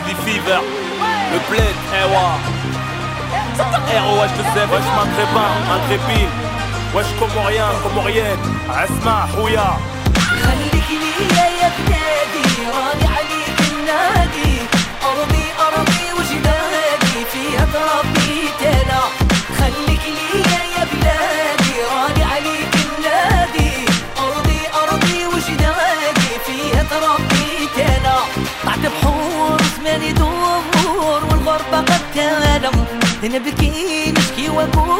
# North African